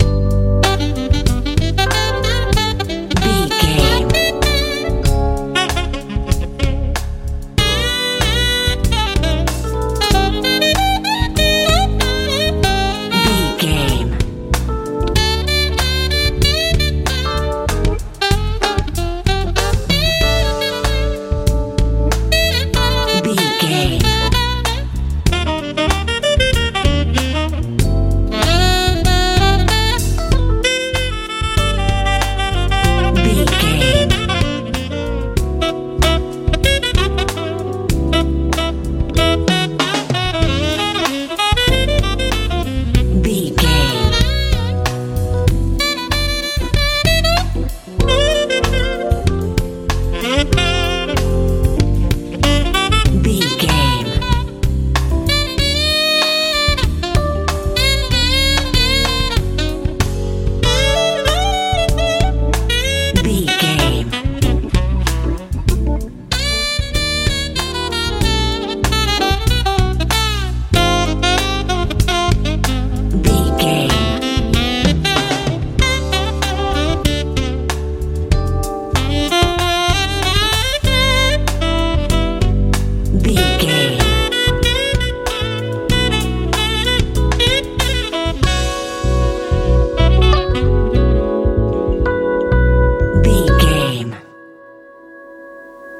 smooth pop ballad
Ionian/Major
groovy
funky
piano
saxophone
bass guitar
drums
romantic
relaxed
soft